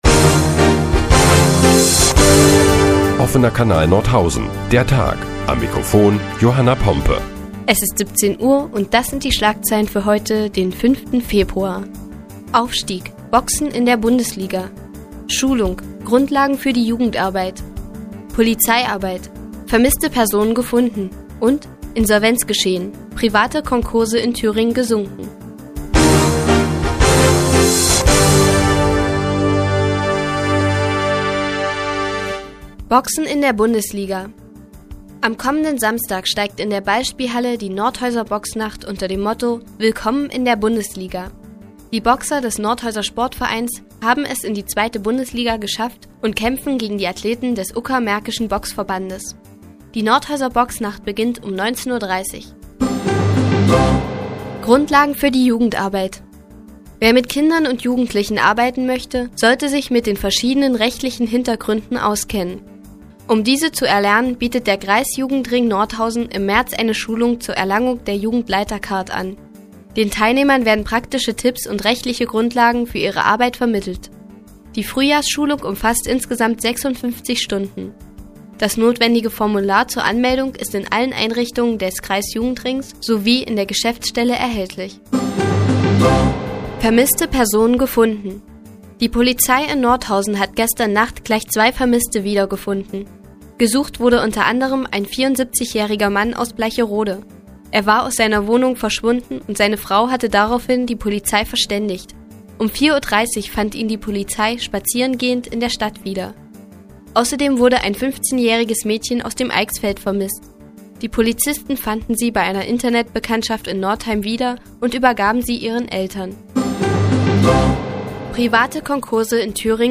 Die tägliche Nachrichtensendung des OKN ist nun auch in der nnz zu hören. Heute geht es unter anderem um Boxen in der Bundesliga und zwei vermisste Personen.